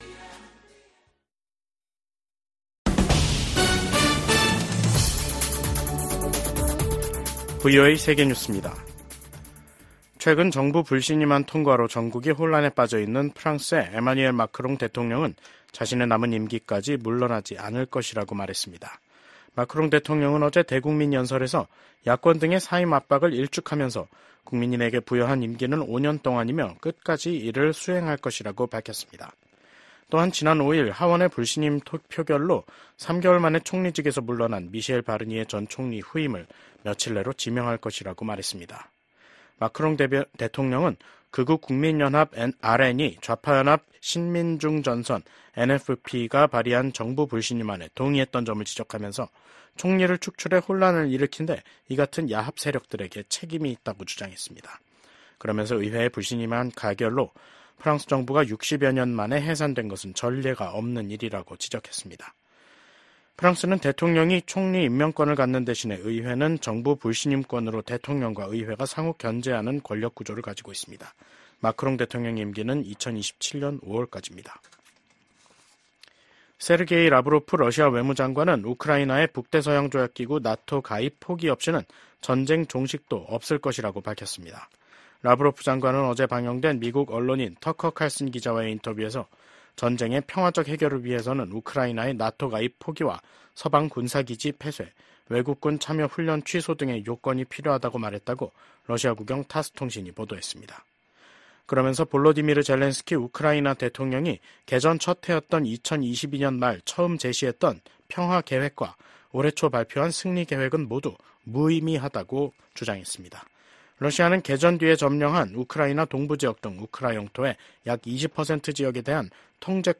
VOA 한국어 간판 뉴스 프로그램 '뉴스 투데이', 2024년 12월 6일 3부 방송입니다. 윤석열 대통령 탄핵소추안에 대한 국회 표결을 하루 앞두고 한국 내 정국은 최고조의 긴장으로 치닫고 있습니다. 미국 국무부는 한국이 대통령 탄핵 절차에 돌입한 것과 관련해 한국의 법치와 민주주의를 계속 지지할 것이라고 밝혔습니다. 한국의 계엄 사태와 관련해 주한미군 태세에는 변함이 없다고 미국 국방부가 강조했습니다.